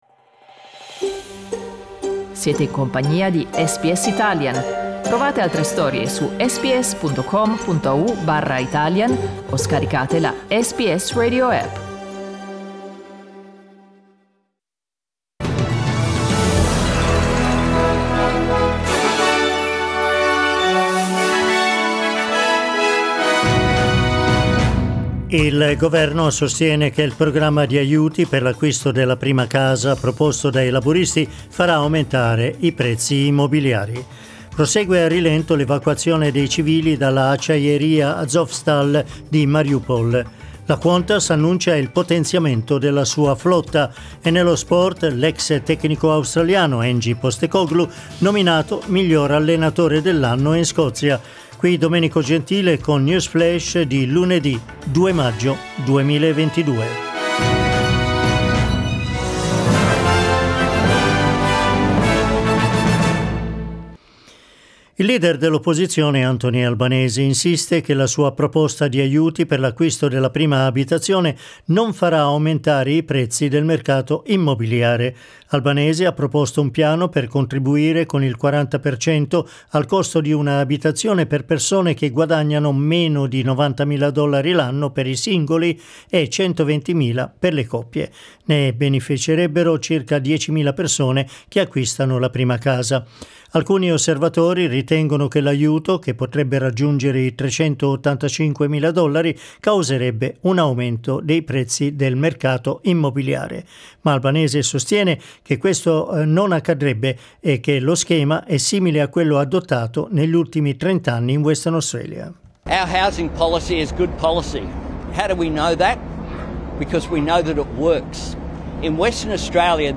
News flash lunedì 2 maggio 2022